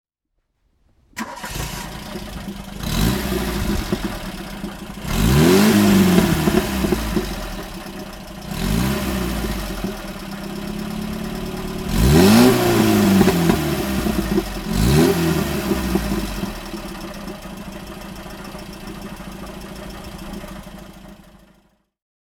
Talbot Matra Murena 2.2 (1982) - Starten und Leerlauf